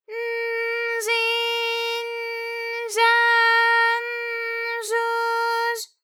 ALYS-DB-001-JPN - First Japanese UTAU vocal library of ALYS.
j0_n_j0i_n_j0a_n_j0u_j0.wav